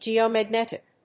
/ˌdʒiomæˈgnɛtɪk(米国英語), ˌdʒi:əʊmæˈgnetɪk(英国英語)/
アクセント・音節gèo・magnétic